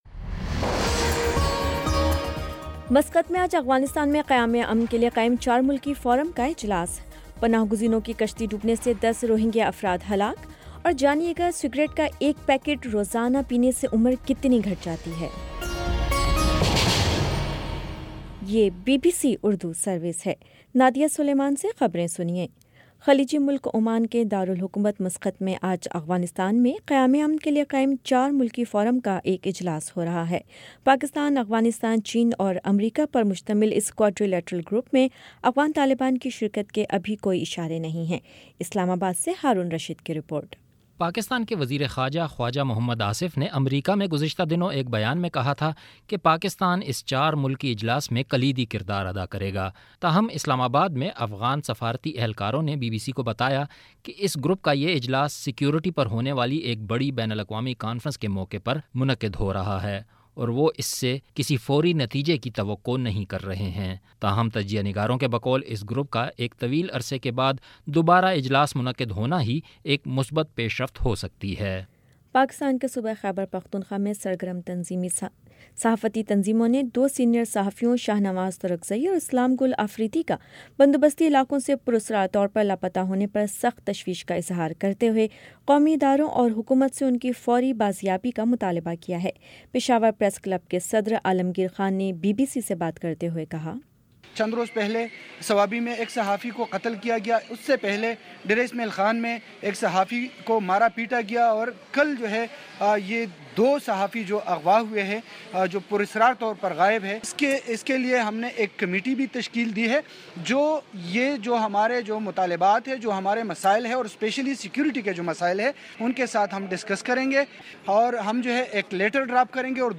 اکتوبر 16 : شام سات بجے کا نیوز بُلیٹن